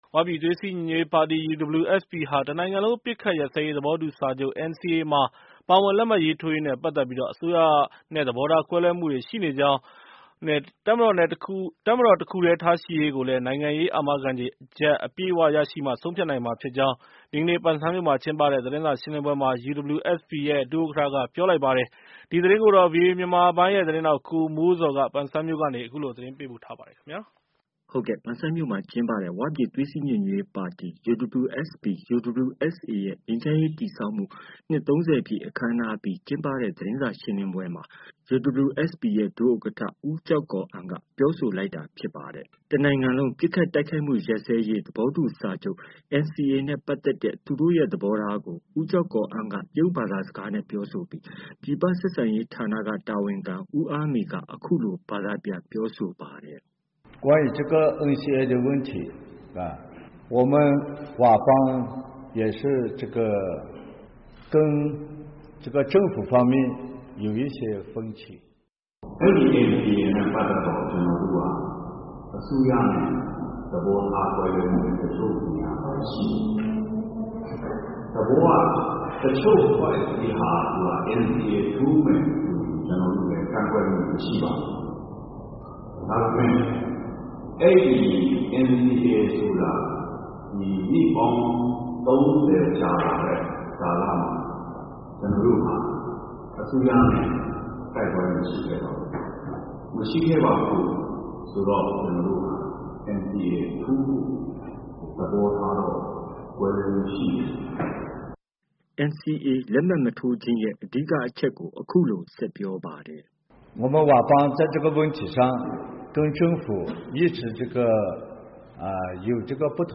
UWSP ဟာ NCA မှာ ပါဝင်လက်မှတ်ရေးထိုးရေးနဲ့ပတ်သက်ပြီး အစိုးရနဲ့သဘောထားကွဲလွဲမှုရှိနေကြောင်း နဲ့ တပ်မတော် တစ်ခုတည်းထားရှိရေးကိုလည်း နိုင်ငံရေး အာမခံချက်တွေ အပြည့်အ၀ ရရှိမှ ဆုံးဖြတ်နိုင်မှာဖြစ်ကြောင်း ဒီကနေ့ ပန်ဆန်းမြို့မှာ ကျင်းပတဲ့သတင်းစာရှင်းလင်းပွဲမှာ UWSP ရဲ့ ဒုဥက္ကဌက ပြောဆိုပါတယ်။